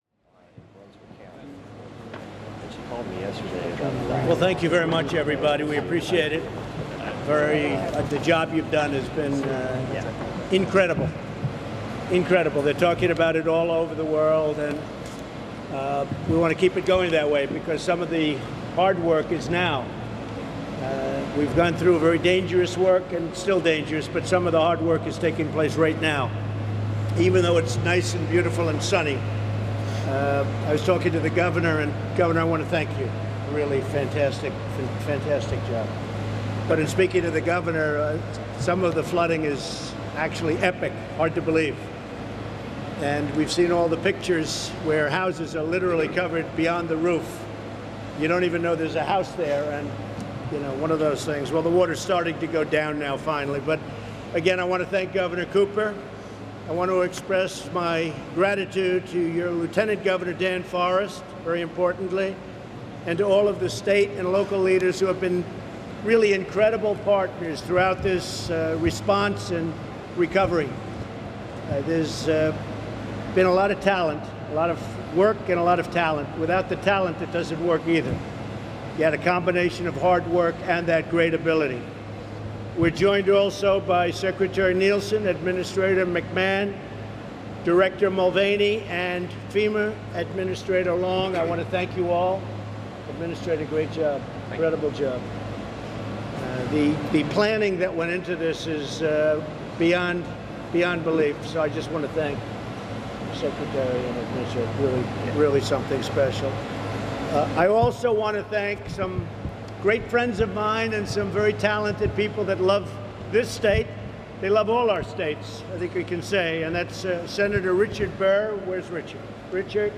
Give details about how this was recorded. Held at Marine Corps Air Station at Cherry Point, NC.